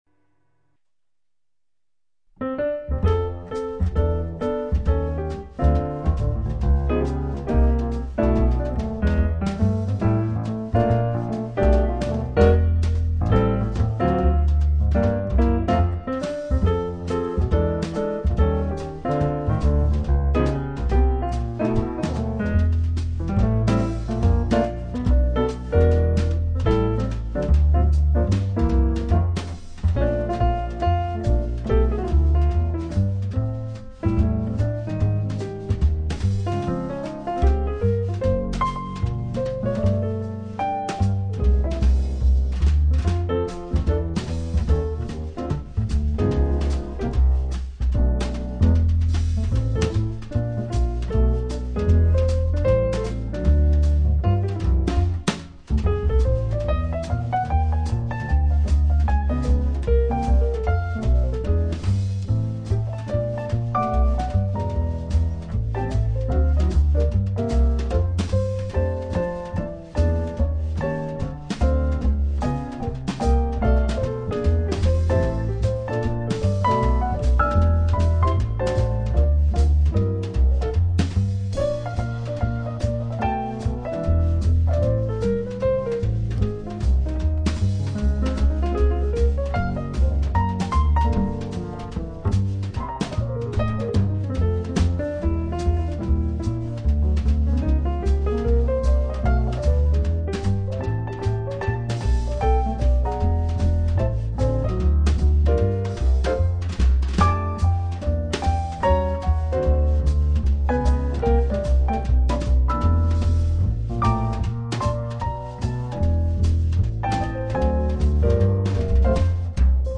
Trio: Acoustic piano